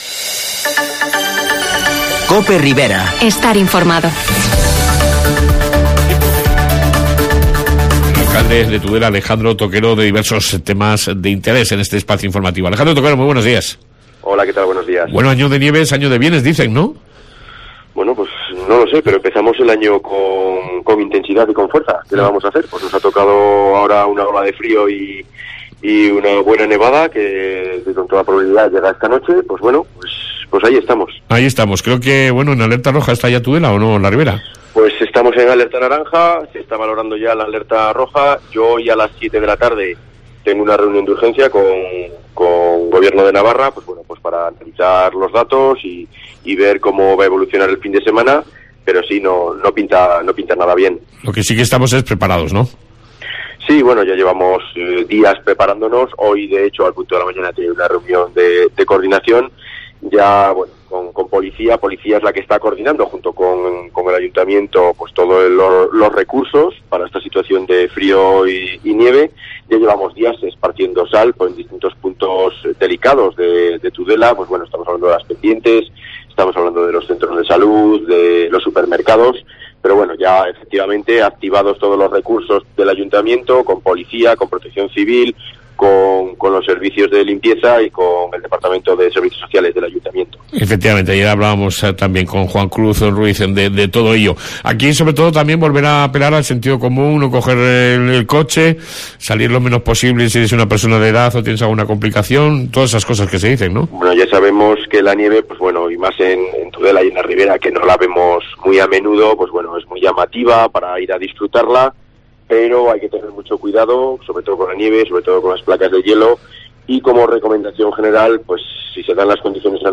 AUDIO: En Mediodía Cope Ribera hablamos con el Alcalde de Tudela sobre Nieve, Covid y el futuro estadio Ciudad de Tudela.